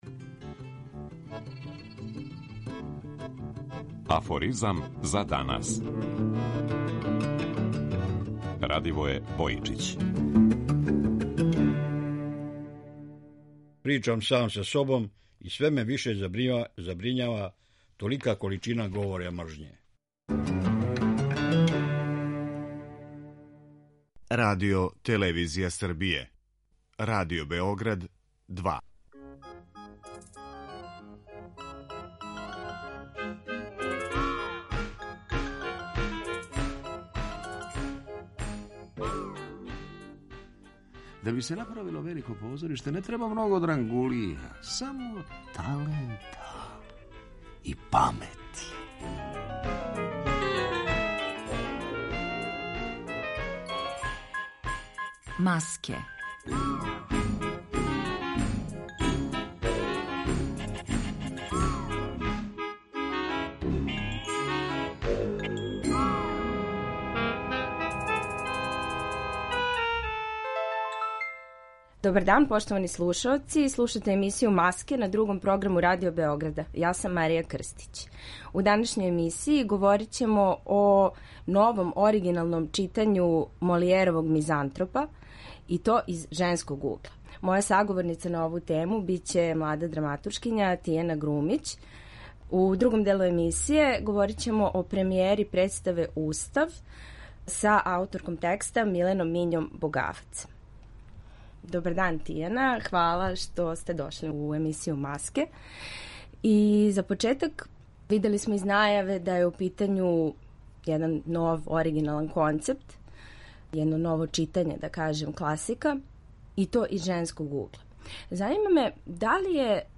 У наставку емисије чућете разговор